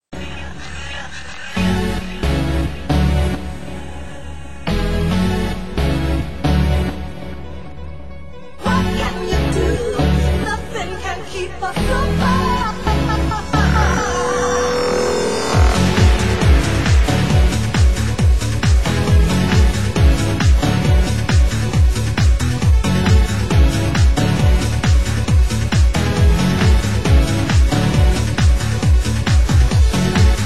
Genre: UK House
Extended Mix, Club Mix